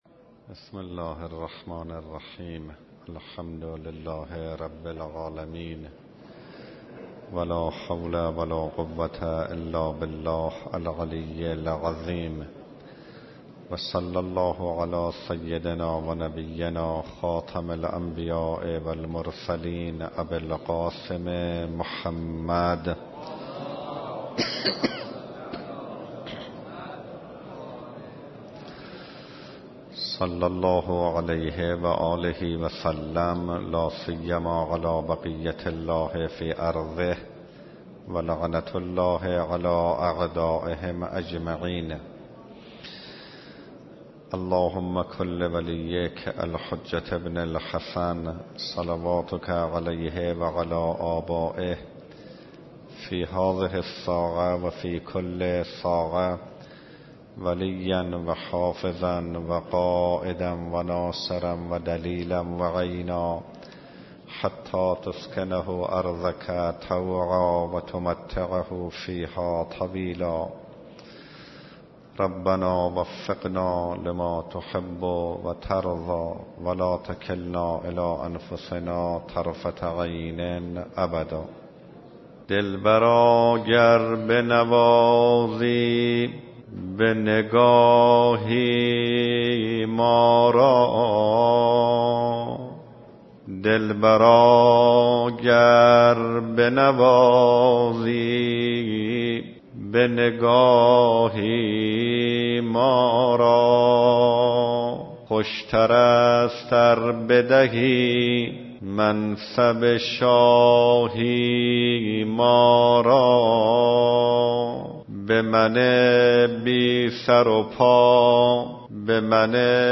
سخنرانيهاي ماه مبارك رمضان